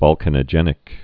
(vŏlkə-nə-jĕnĭk, vôl-)